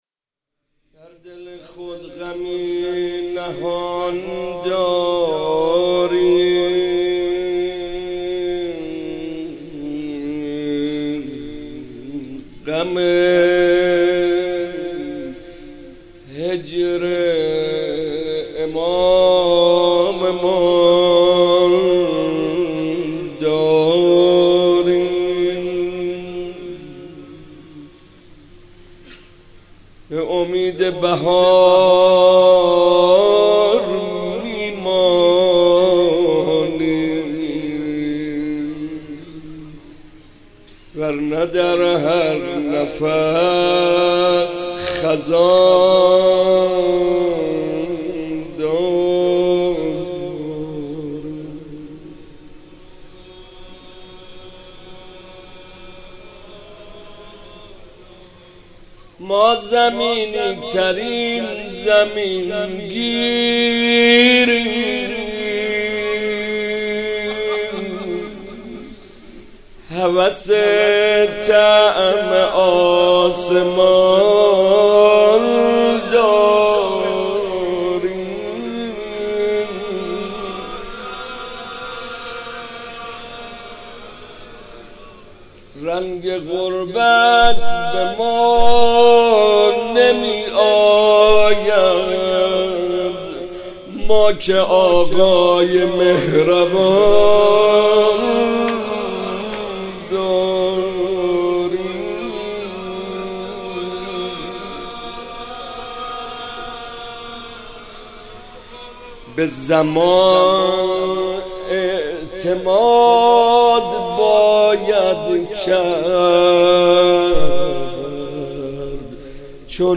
مناسبت : شب بیست و دوم رمضان
قالب : مناجات